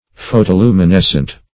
Search Result for " photoluminescent" : The Collaborative International Dictionary of English v.0.48: Photoluminescent \Pho`to*lu`mi*nes"cent\, a. [Photo- + luminescent.]
photoluminescent.mp3